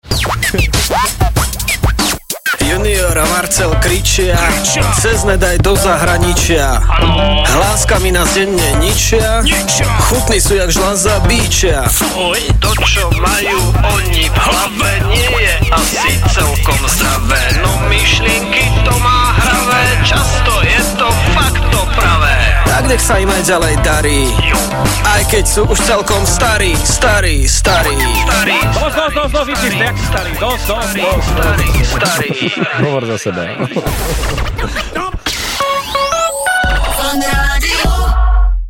Rapsong